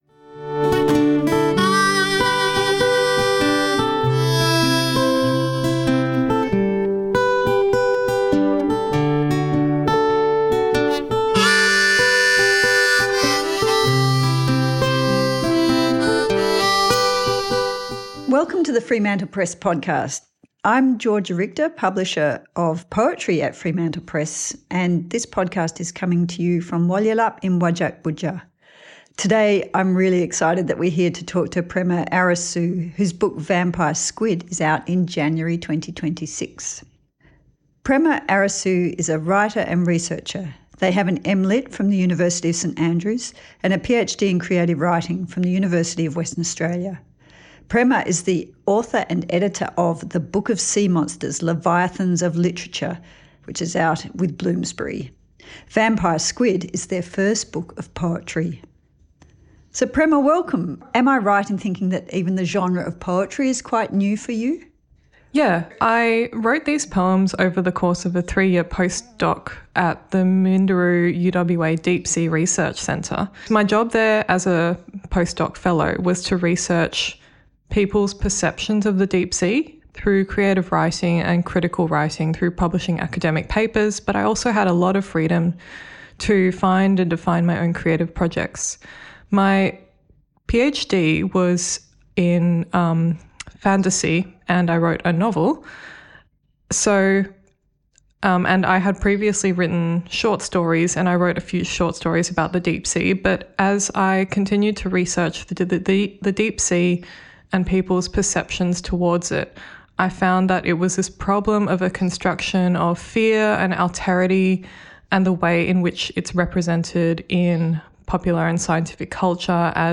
and reads some poems from the book.